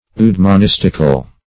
Search Result for " eudaemonistical" : The Collaborative International Dictionary of English v.0.48: Eudemonistical \Eu*de`mon*is"tic*al\, Eudaemonistical \Eu*d[ae]`mon*is"tic*al\, a. Eudemonistic.
eudaemonistical.mp3